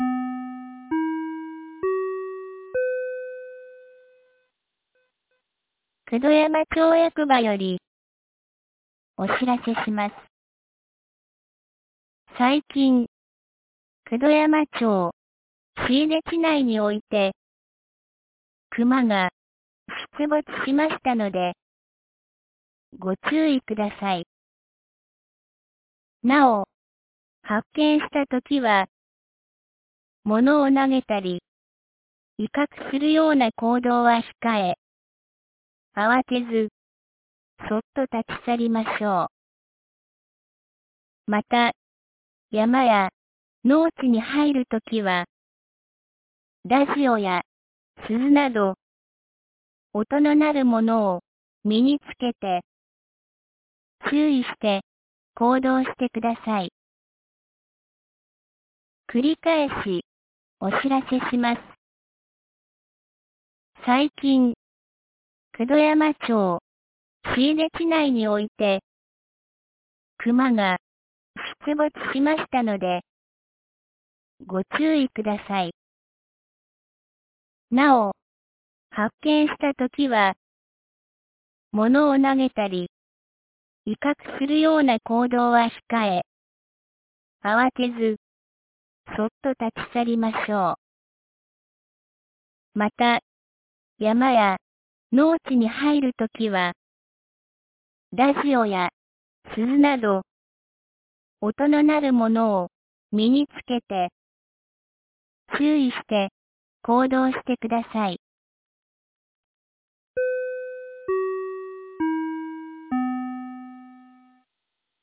2024年11月22日 13時02分に、九度山町より椎出地区、下古沢地区、中古沢地区、上古沢地区、笠木地区へ放送がありました。
放送音声